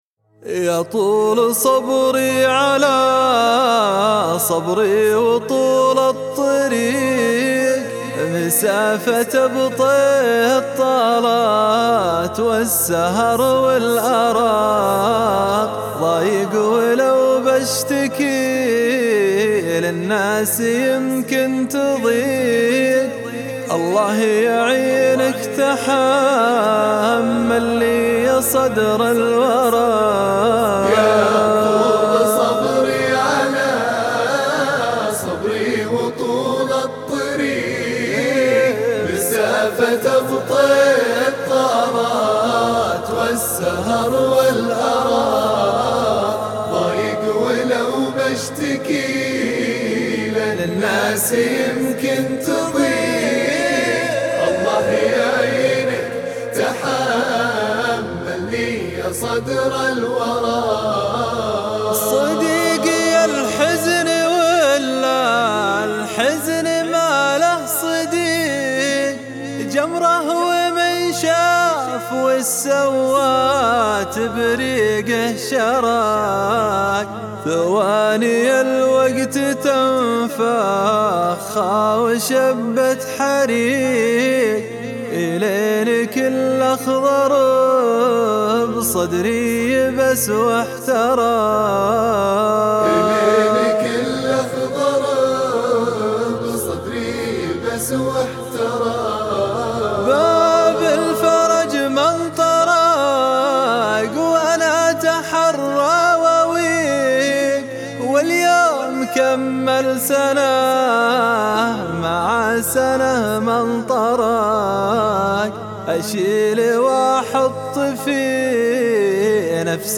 الشيله